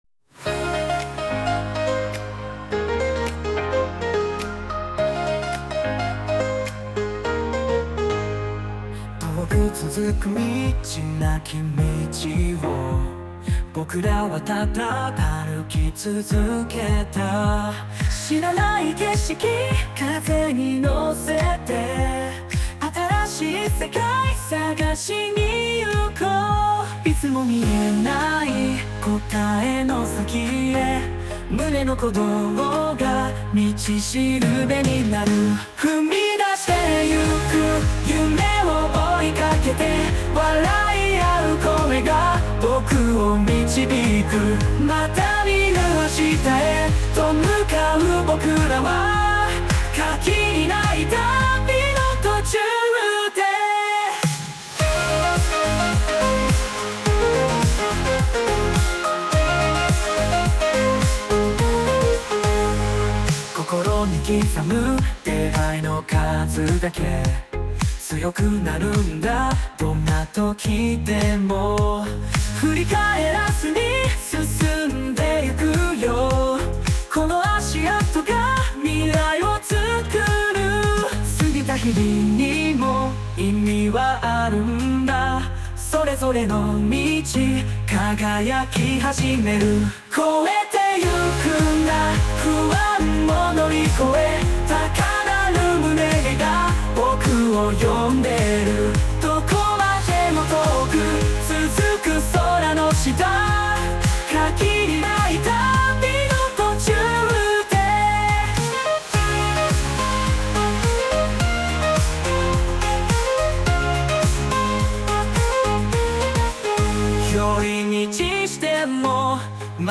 著作権フリーオリジナルBGMです。
男性ボーカル（邦楽・日本語）曲です。
ウェディングソングではありませんが、前向きな曲なので、結婚式でもその他でも何にでも合うと思います♪♪